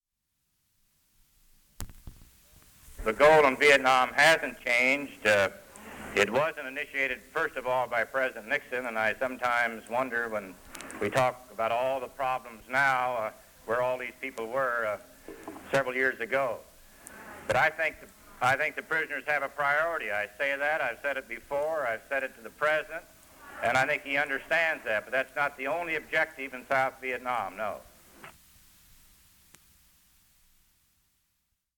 Senator Bob Dole explains that he believes the POWs (prisoners of war) in Vietnam are a priority, but that there are also other goals in Vietnam.
radio programs